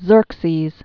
(zûrksēz) Known as "Xerxes the Great." 519?-465 BC.